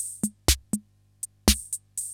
CR-68 LOOPS3 3.wav